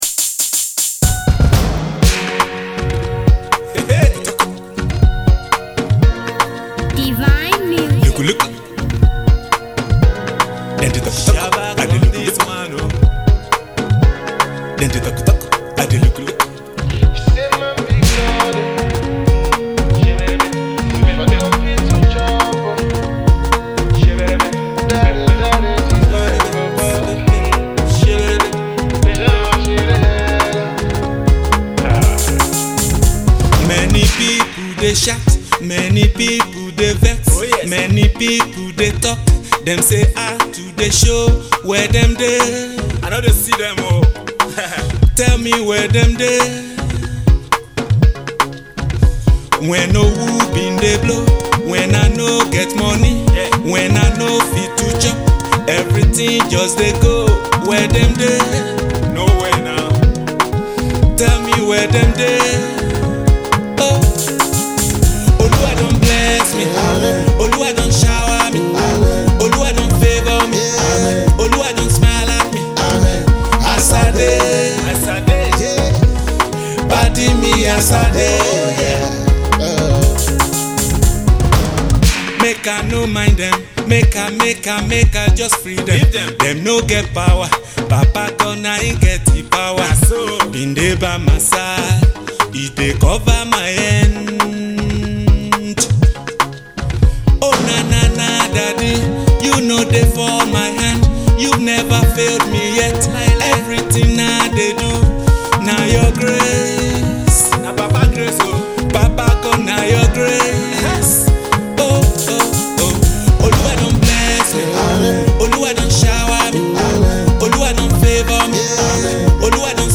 Multi-talented American based gospel music artiste
Afro-pop gospel jam